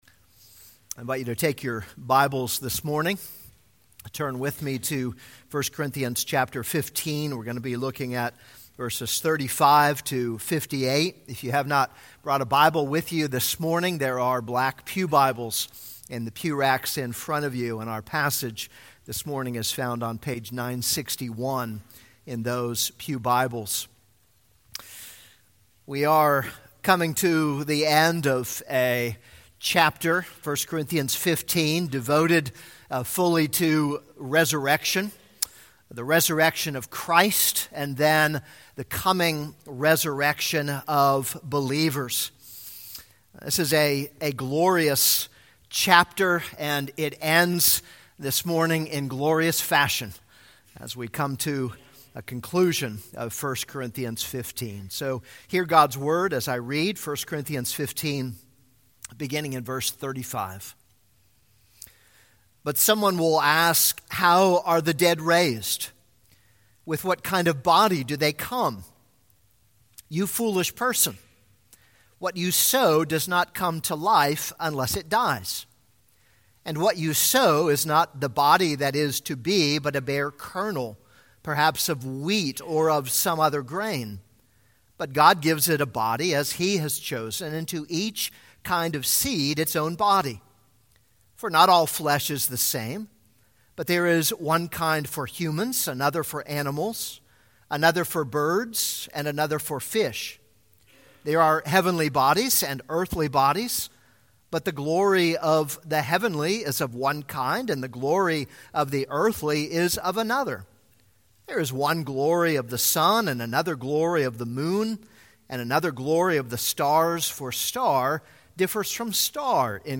This is a sermon on 1 Corinthians 15:35-58.